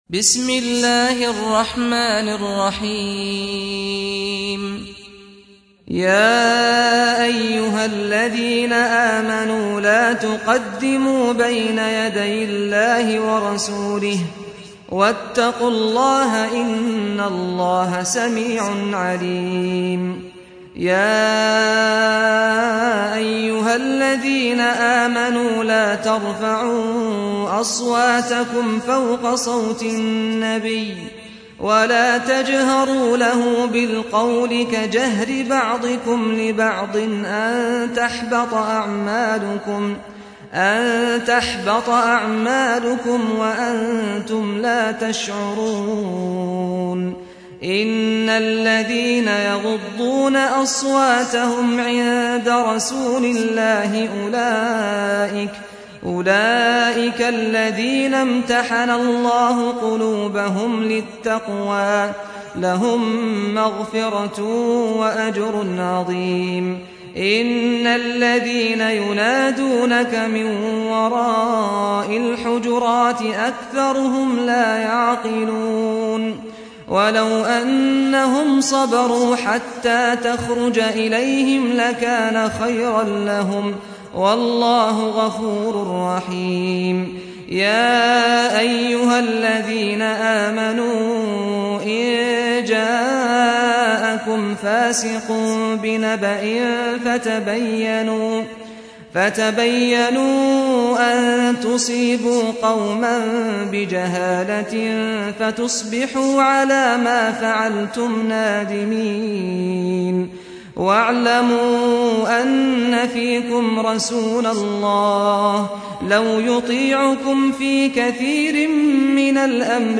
سُورَةُ الحِجۡرِات بصوت الشيخ سعد الغامدي